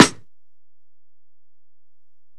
Snare (17).wav